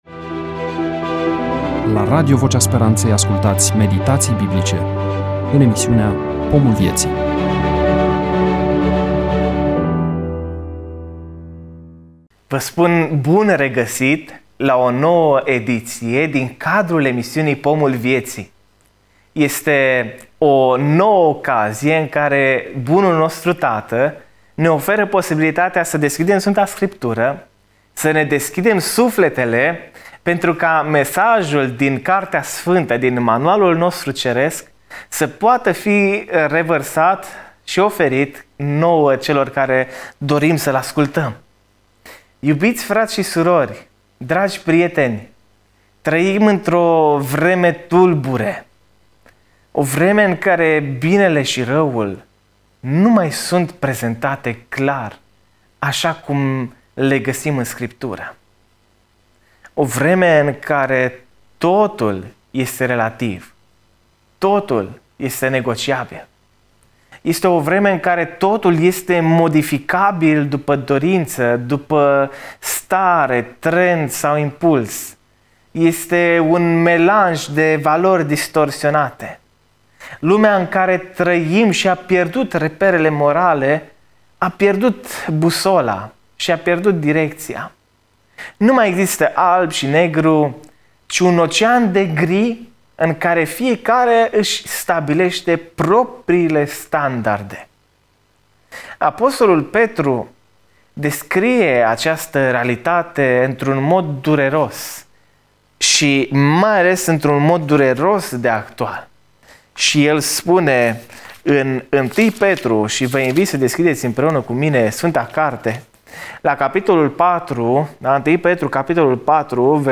EMISIUNEA: Predică DATA INREGISTRARII: 13.02.2026 VIZUALIZARI: 41